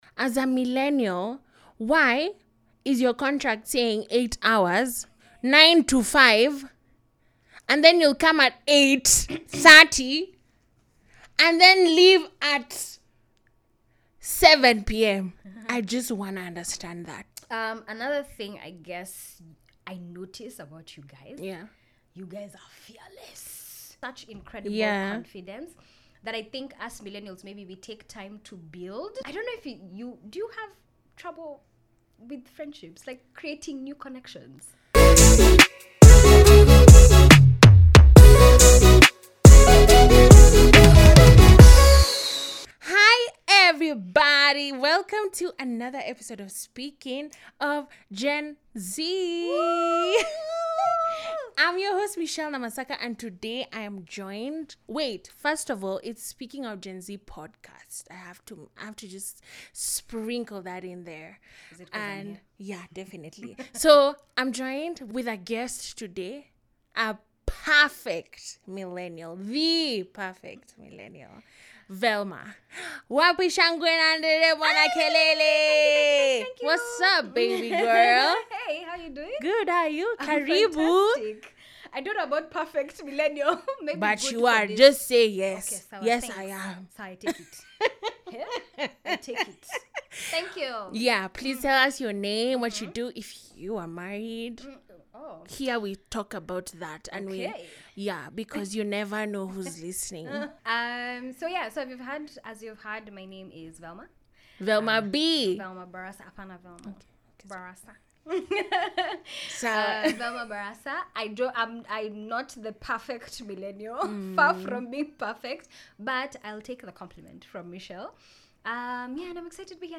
Speaking of Gen Z brings you entertaining but meaningful conversations about a generation that is trying to navigate through adulthood.